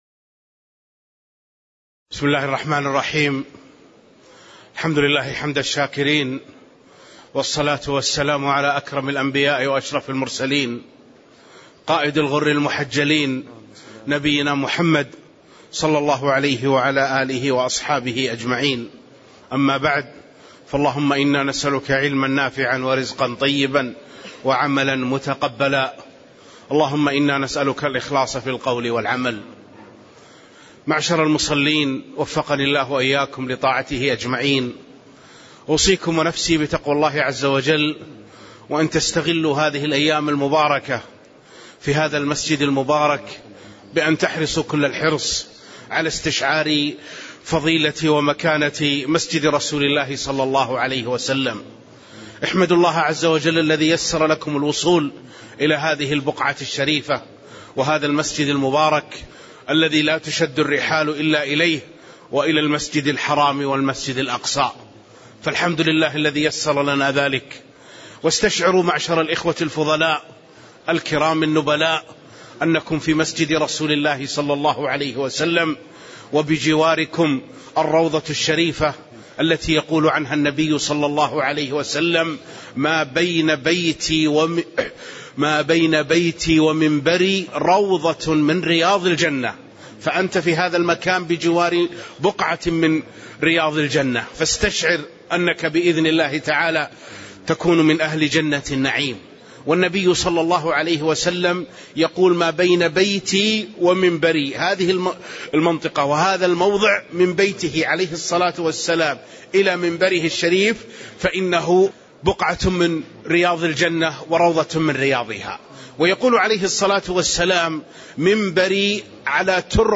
تاريخ النشر ٢٩ ربيع الأول ١٤٣٧ هـ المكان: المسجد النبوي الشيخ